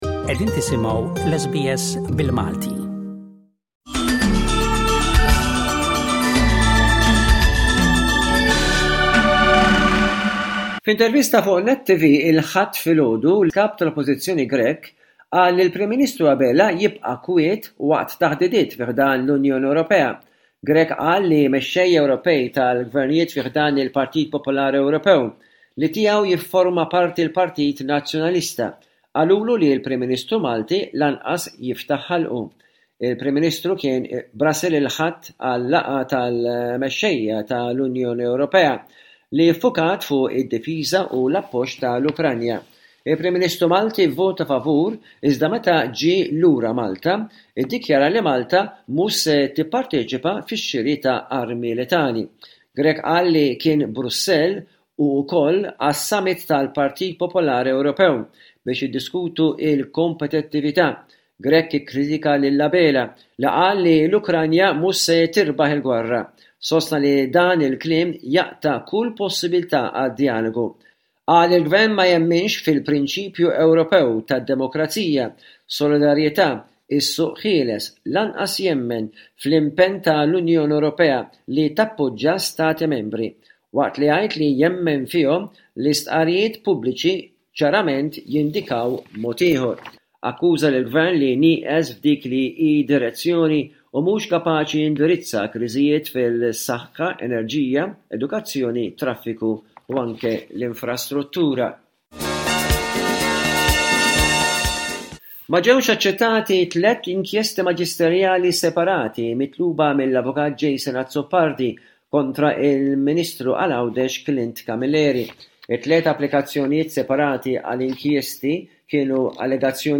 Bullettin ta' aħbarijiet minn Malta